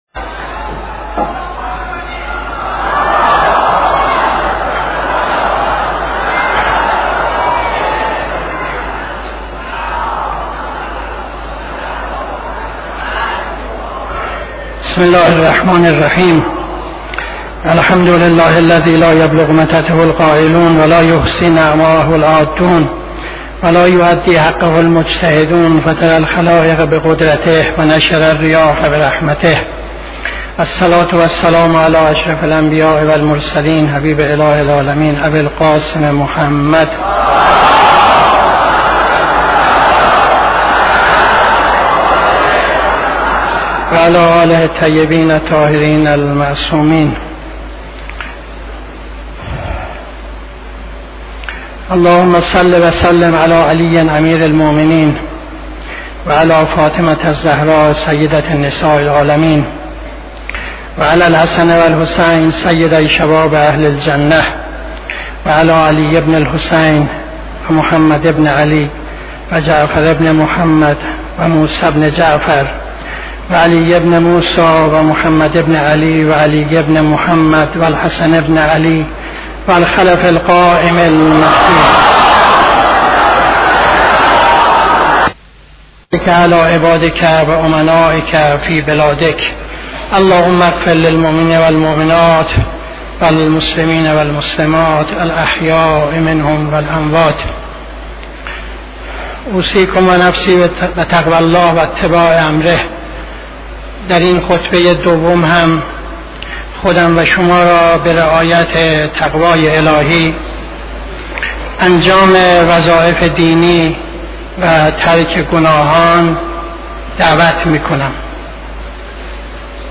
خطبه دوم نماز جمعه 28-02-75